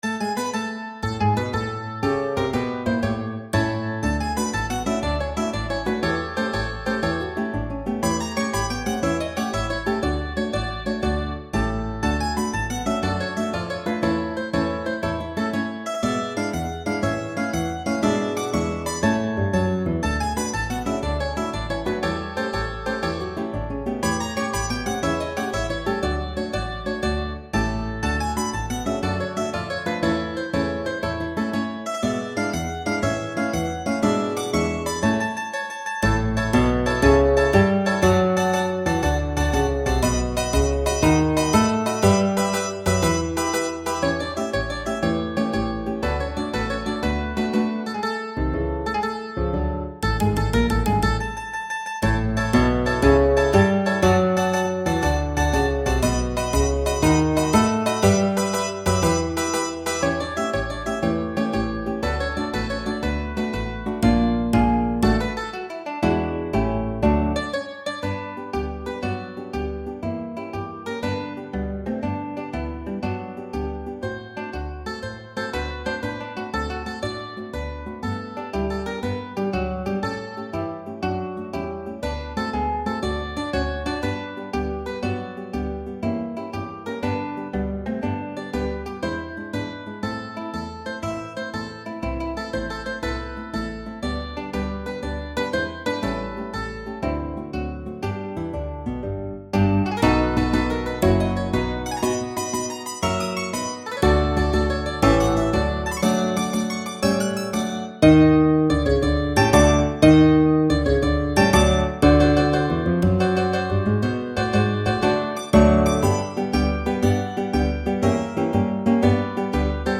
computer-generated practice file